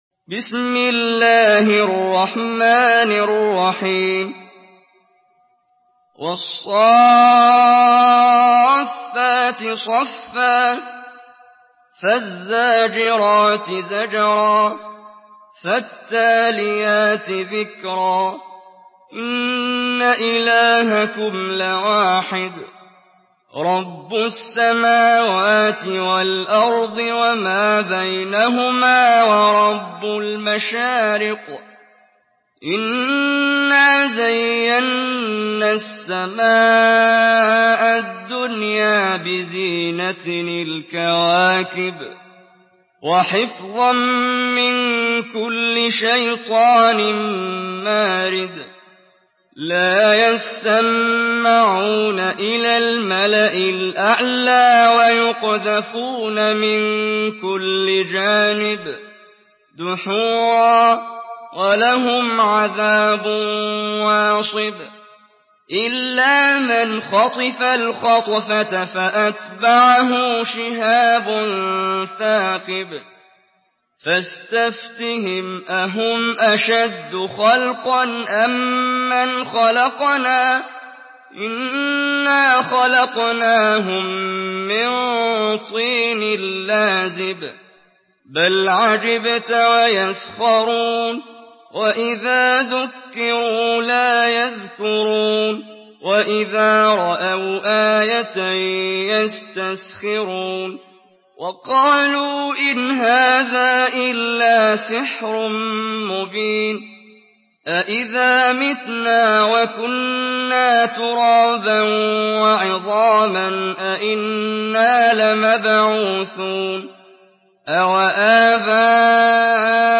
سورة الصافات MP3 بصوت محمد جبريل برواية حفص
مرتل